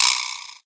cf_jawbone.ogg